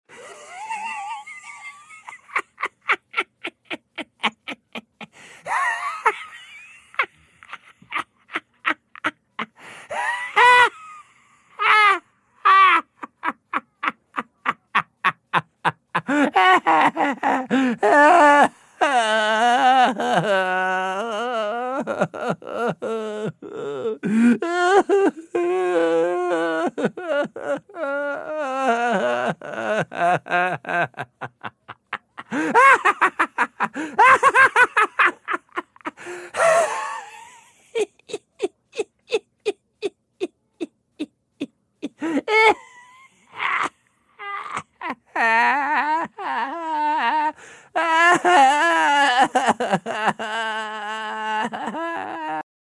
Категория: Атмосфера ужаса
Идиотский смех человека с симптомами дегенерата.